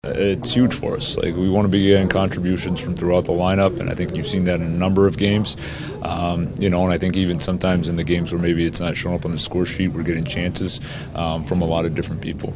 Coach Dan Muse says the Pens are getting goals and scoring chances from all four lines.